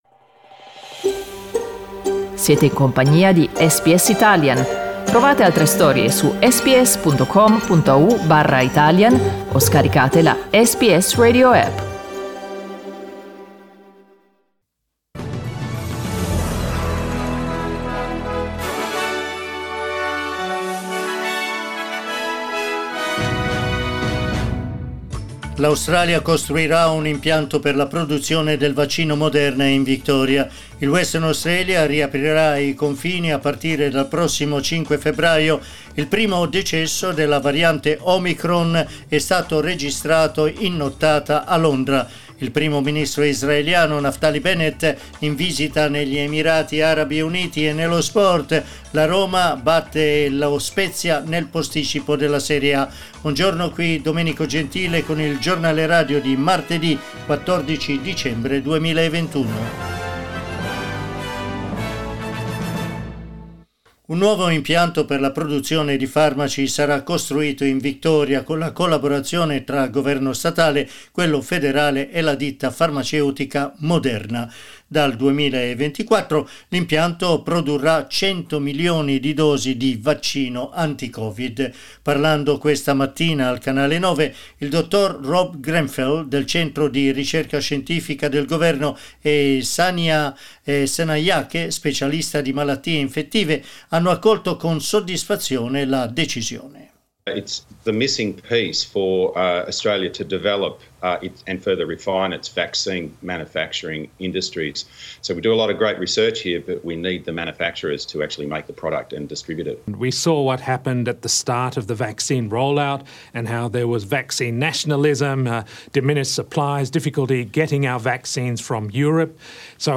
Giornale radio martedì 14 dicembre 2021
Il notiziario di SBS in italiano.